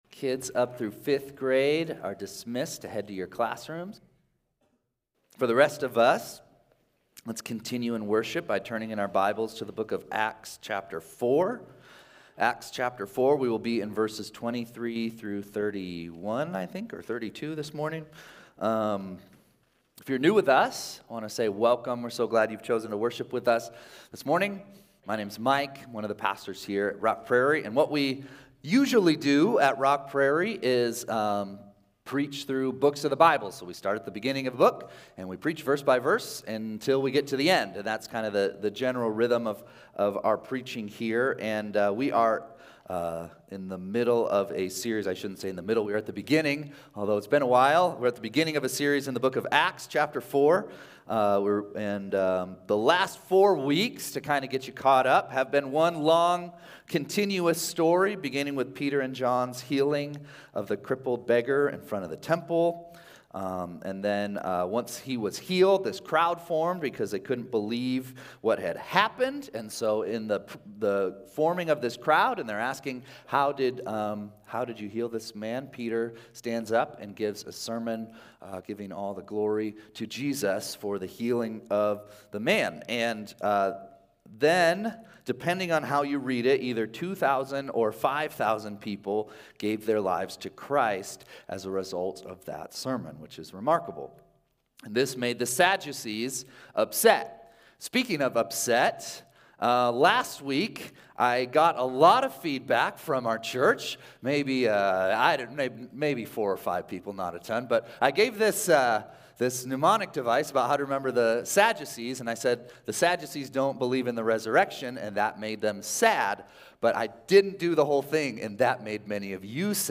3-22-25-Sunday-Service.mp3